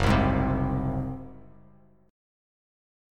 F#sus2b5 chord